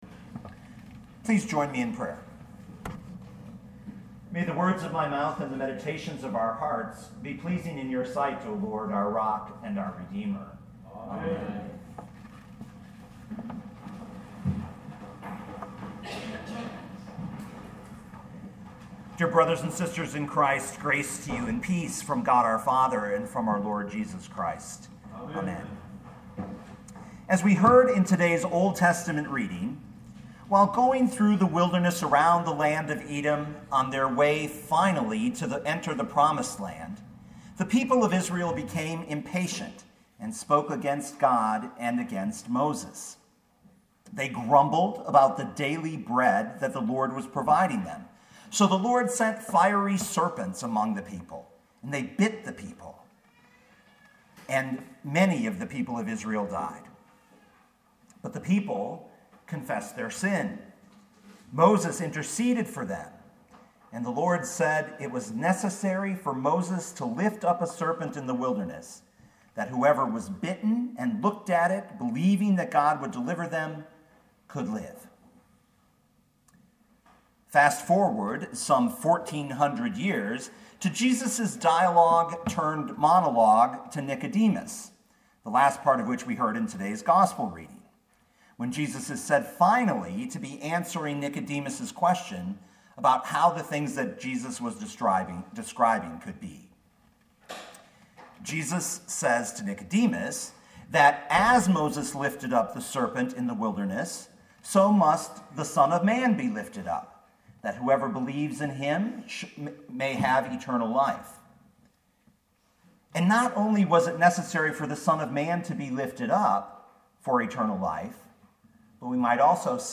2018 John 3:14-21 Listen to the sermon with the player below, or, download the audio.